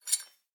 spoongrab.ogg